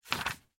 sfx_pop_window.mp3